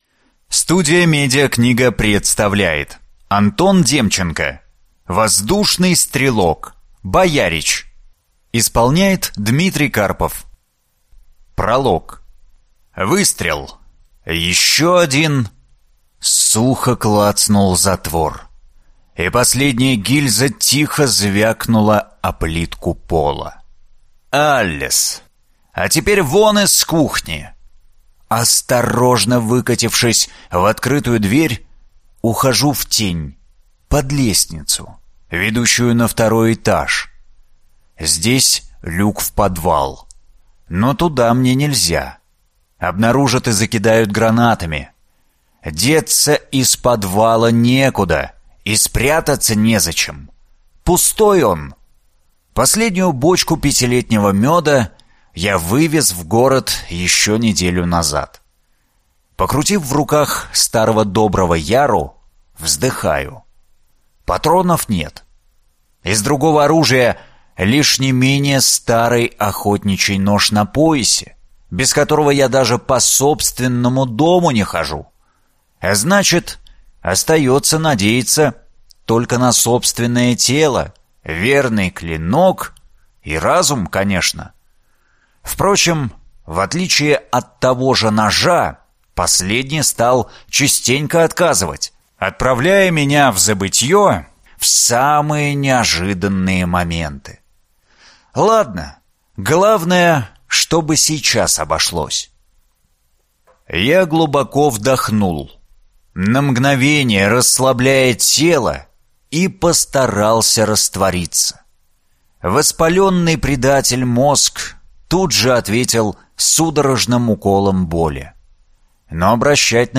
Аудиокнига Воздушный стрелок. Боярич | Библиотека аудиокниг